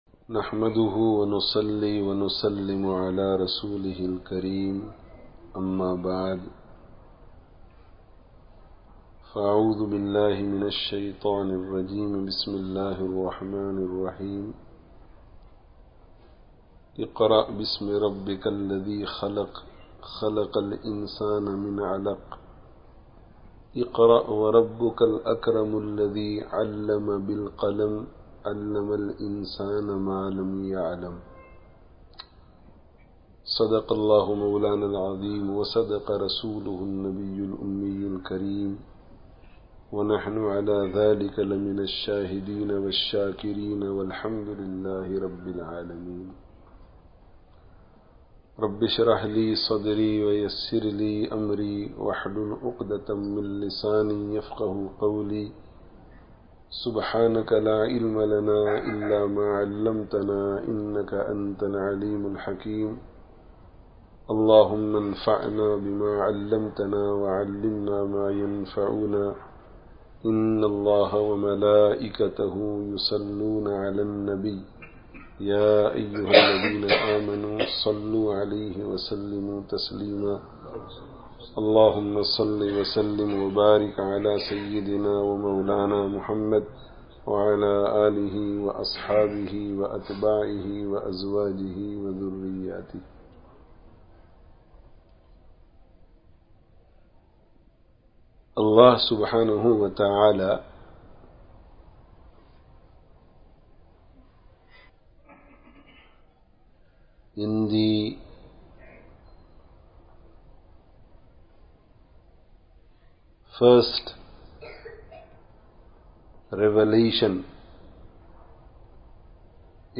Importance of Learning and Teaching (Madarasah Al Madaniyyah, St Matthews,Leicester 16/09/15)